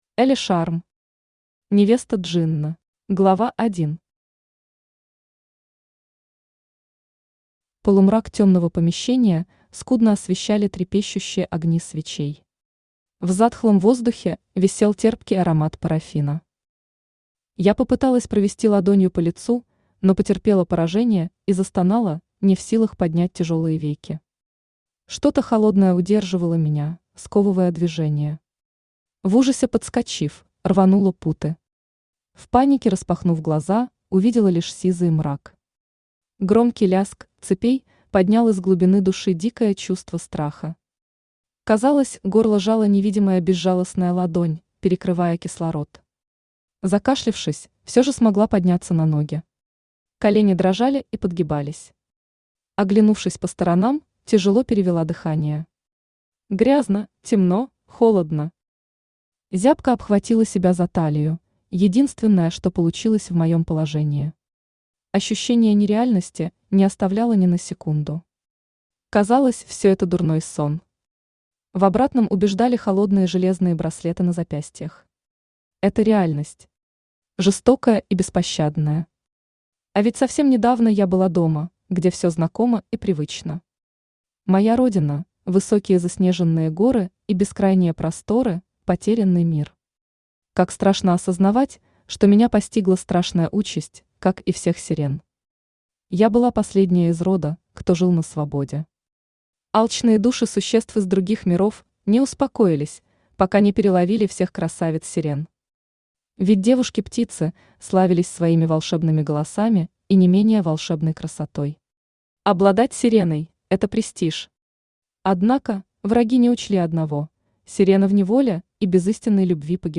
Аудиокнига Невеста Джинна | Библиотека аудиокниг
Aудиокнига Невеста Джинна Автор Элли Шарм Читает аудиокнигу Авточтец ЛитРес.